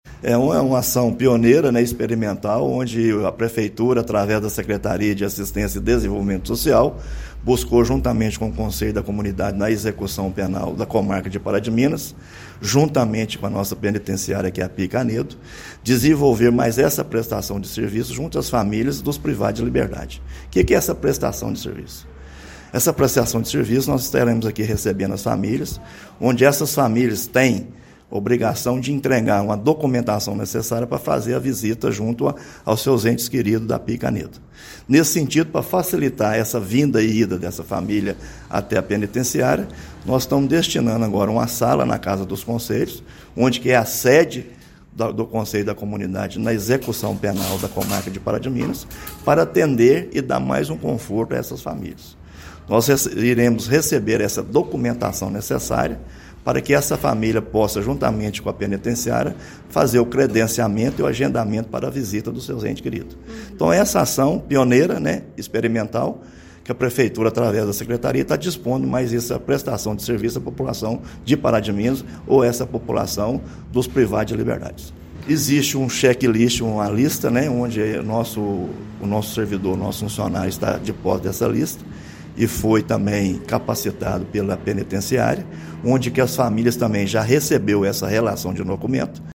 No ponto de apoio eles terão agora suporte para agendar as visitas e também serão instruídos sobre os procedimentos na penitenciária. Isto também vai reduzir custos para este familiar, como explica o secretário Municipal de Assistência e Desenvolvimento Social Flávio Medina Neto: